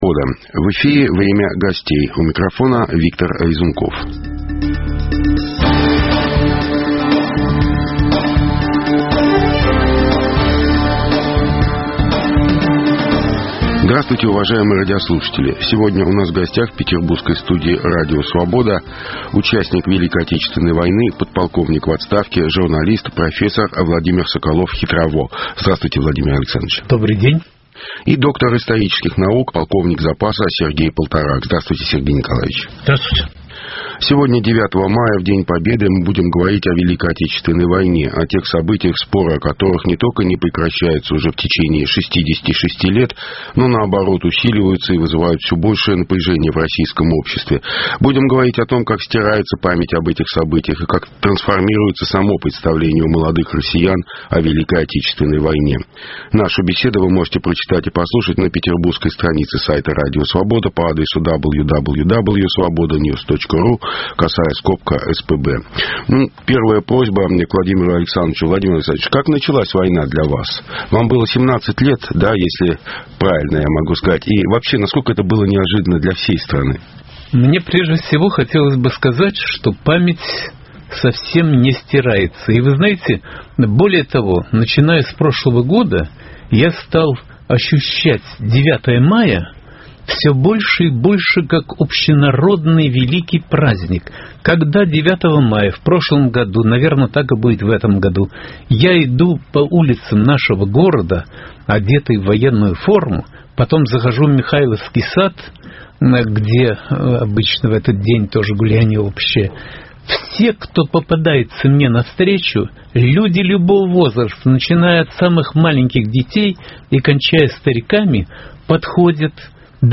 /В записи/.